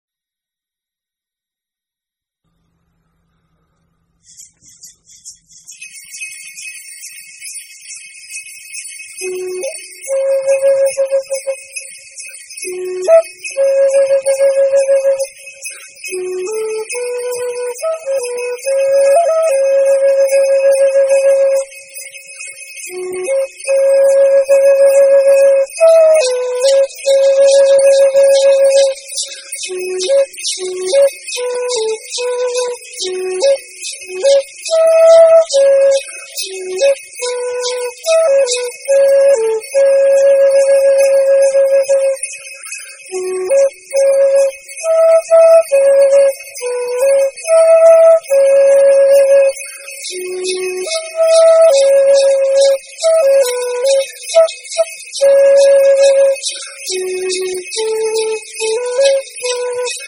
Native American Flute Music and More